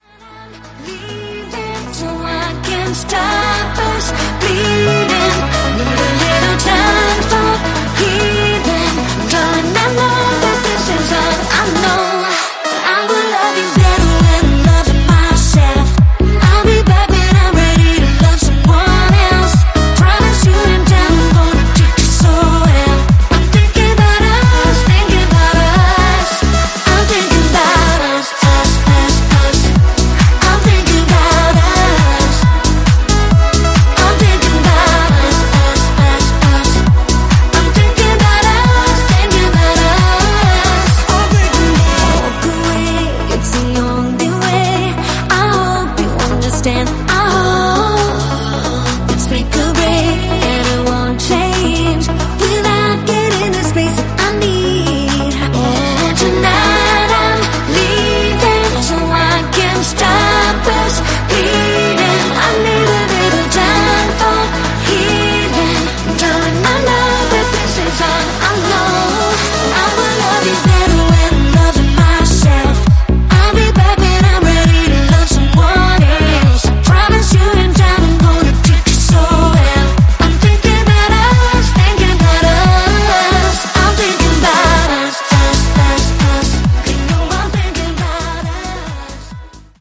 ジャンル(スタイル) POP / HOUSE